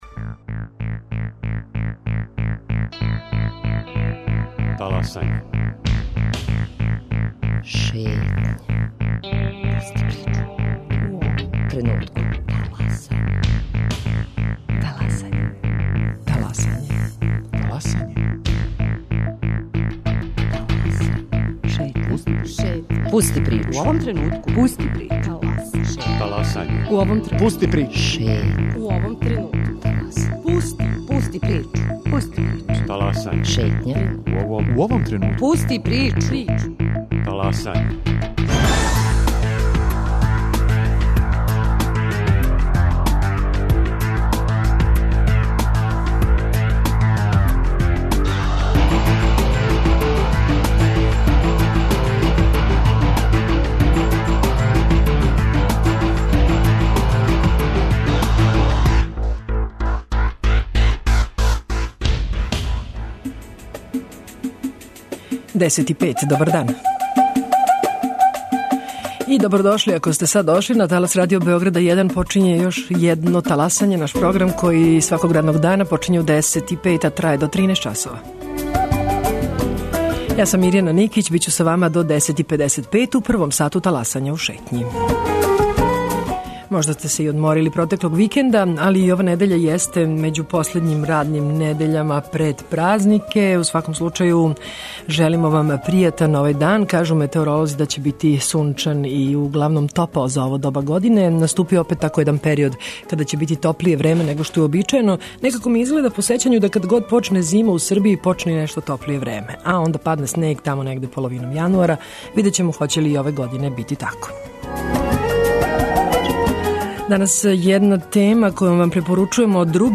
У Шетњи ћете чути и разговор са отправником послова амбасаде Велике Британије у Београду Дејвидом Мекферлејном, који је оценио да је одлуком самита Европске уније да у јануару отвори приступне преговоре са Србијом са правом награђена посвећеност наше земље будућности у Унији.